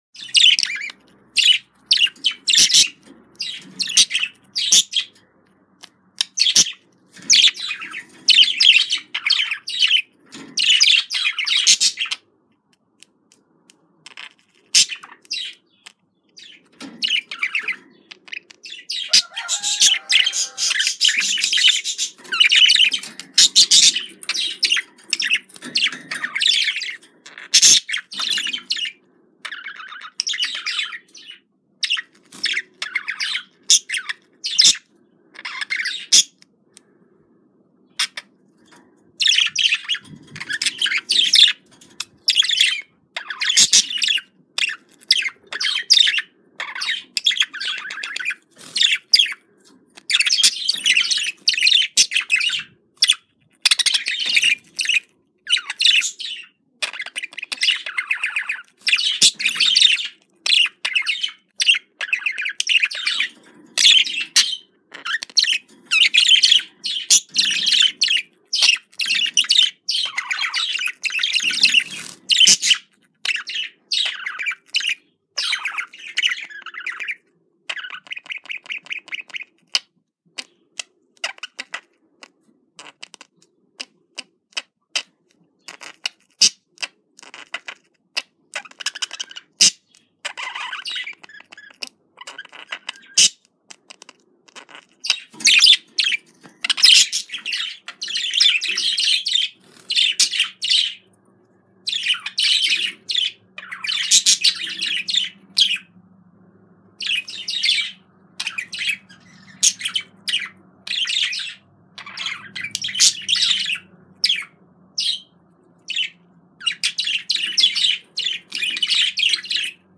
دانلود آهنگ مرغ عشق جفت شده از افکت صوتی انسان و موجودات زنده
جلوه های صوتی
دانلود صدای مرغ عشق جفت شده از ساعد نیوز با لینک مستقیم و کیفیت بالا